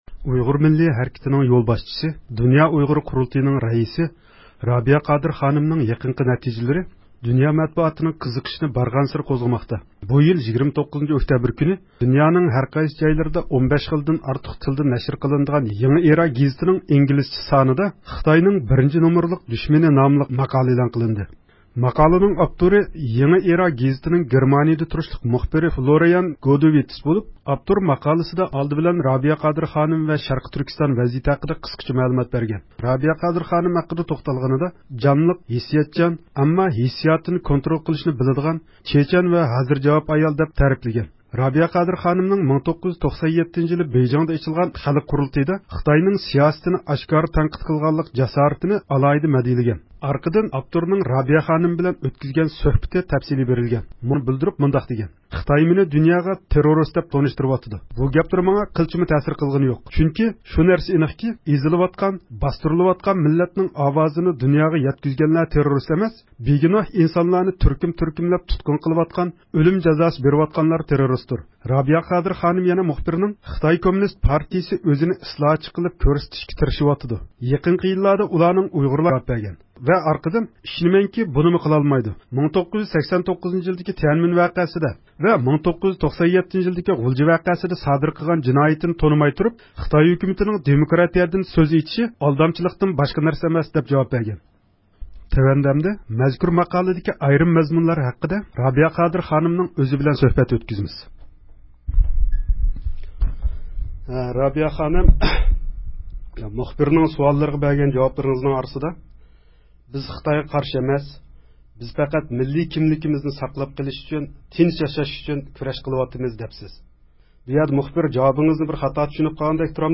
رابىيە قادىر خانىم بىلەن سۆھبەت — يېڭى ئىرا گېزىتىدىكى ماقالە ھەققىدە – ئۇيغۇر مىللى ھەركىتى
ماقالىدا يەنە رابىيە خانىم ئېغىزىدىن بېرىلگەن » بىز خىتايغا قارشى ئەمەس، بىز پەقەت، مىللىي كىملىكىمىزنى قوغداپ قېلىشنى ۋە تېنچ ياشاشنىلا خالايمىز» دېگەن جۈملە بولۇپ، بۇ سۆزى توغرىسىدا ۋە بۇنىڭغا مۇناسىۋەتلىك مەسىلىلەر ئۈستىدە رابىيە خانىمنىڭ ئۆزى بىلەن ئۆتكۈزگەن سۆھبىتىمىزنىڭ مەزمۇنىنى يۇقىرىدىكى ئۇلىنىشتىن ئاڭلايسىلەر.